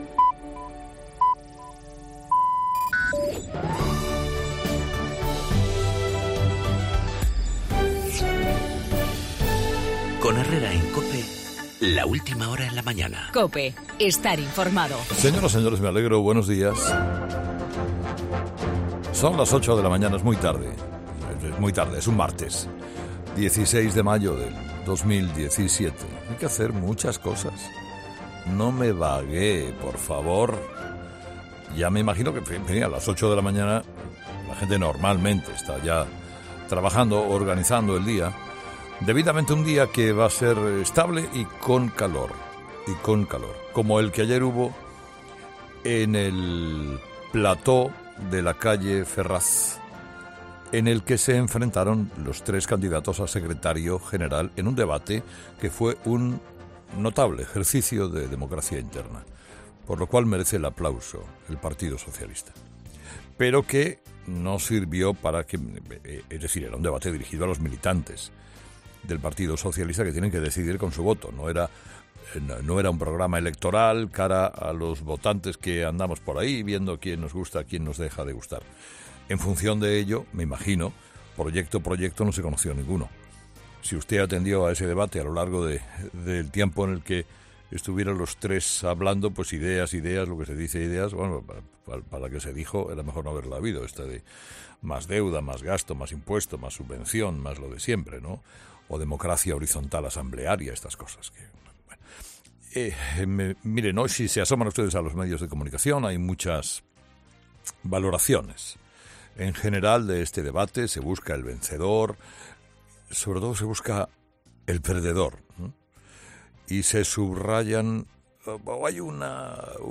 AUDIO: El debate a tres en el PSOE y la negociación del Real Decreto la estiba, en el monólogo de Carlos Herrera a las 8 de la mañana.